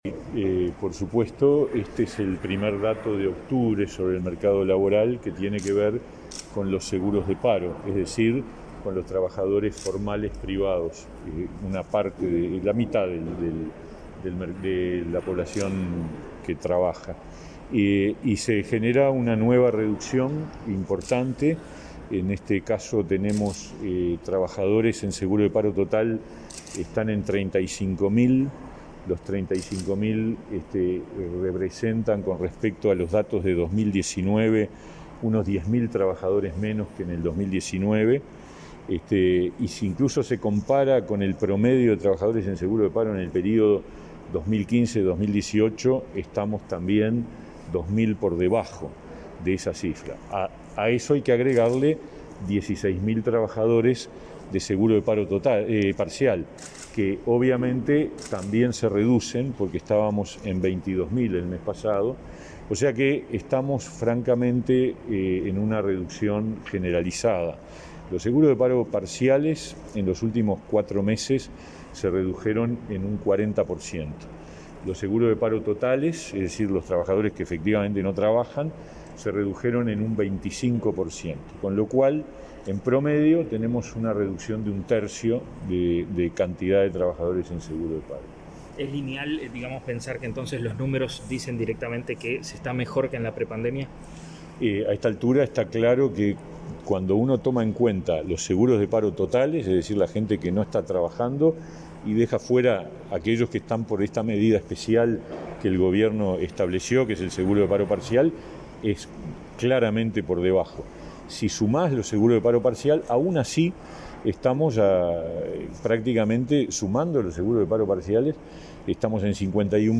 Declaraciones a la prensa del ministro de Trabajo, Pablo Mieres